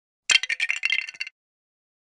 lego break